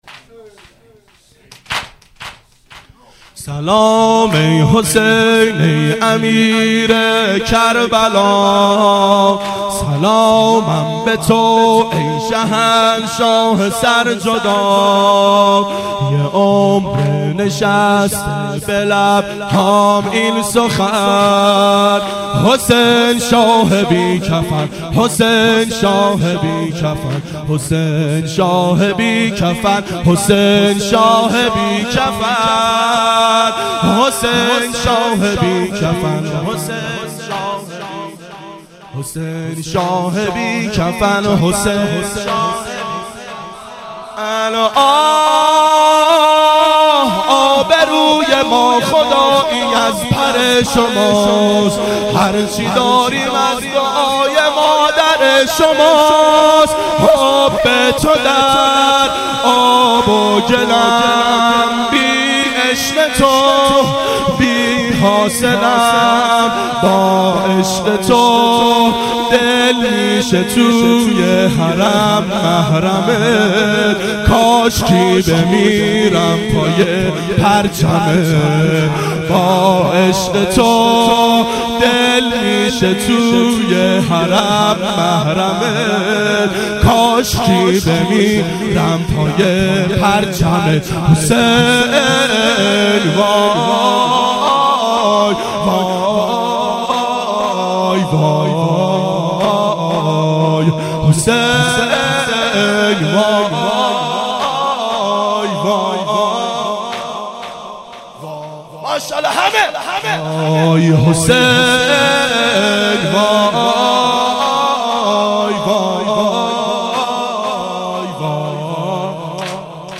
ظهر اربعین سال 1389 محفل شیفتگان حضرت رقیه سلام الله علیها